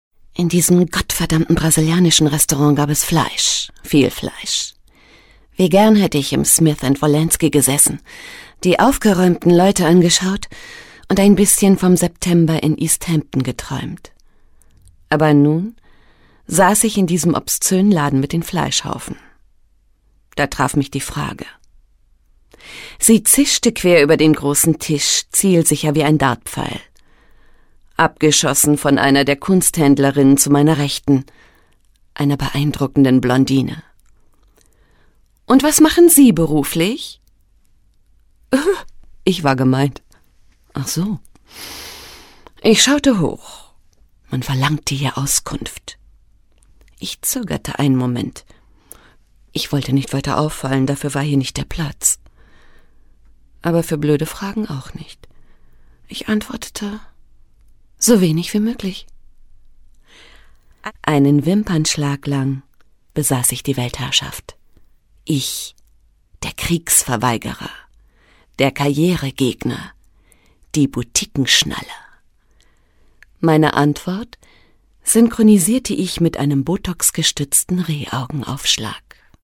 Booking Sprecherin
Werbesprecherin